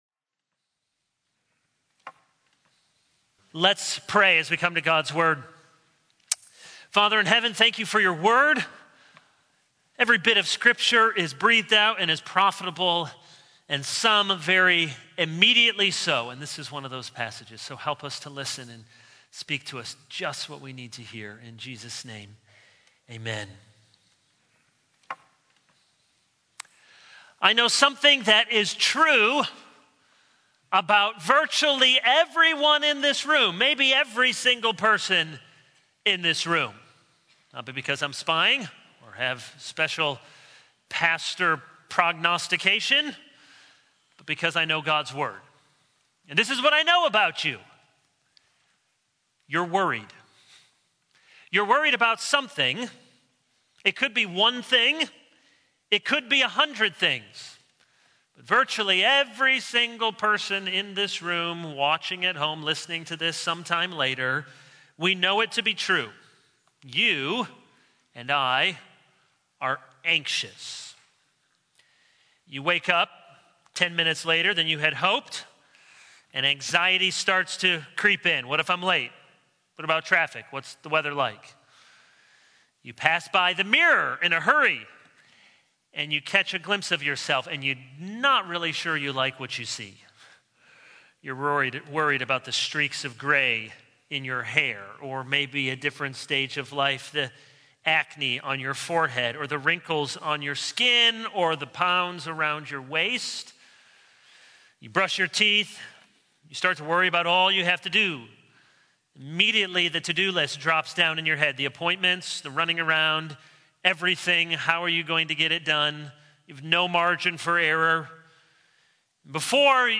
All Sermons Obadiah 0:00 / Download Copied!